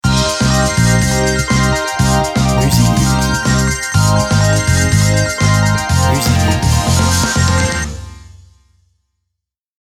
Dynamique, jeunesse, robotique, fun, moderne
BPM Rapide